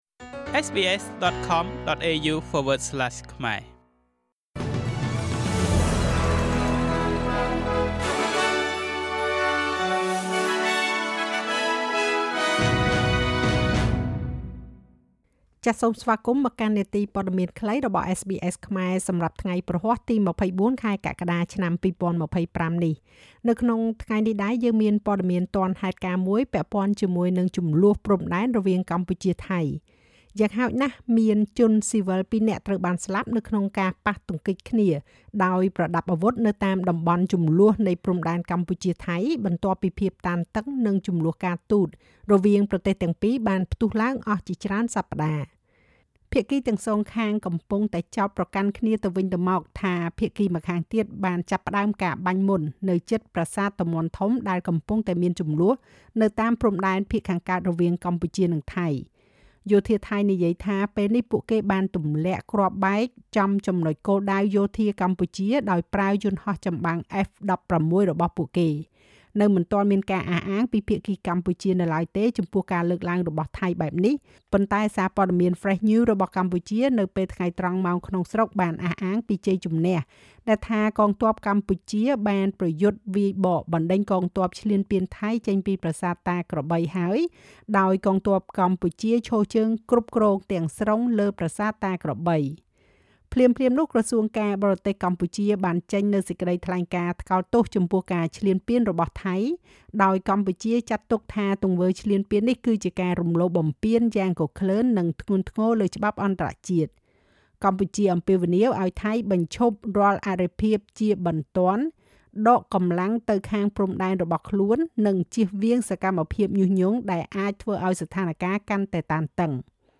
នាទីព័ត៌មានខ្លីរបស់SBSខ្មែរ សម្រាប់ថ្ងៃព្រហស្បតិ៍ ទី២៤ ខែកក្កដា ឆ្នាំ២០២៥